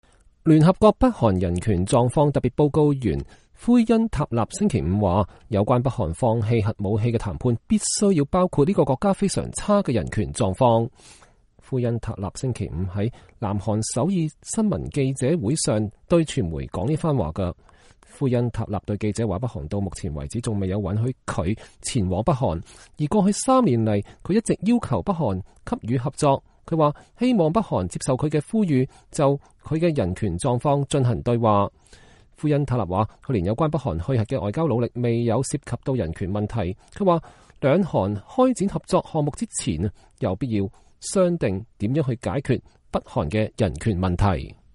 聯合國北韓人權狀況特別報告員奎因塔納1月11日在南韓首爾的記者會上講話。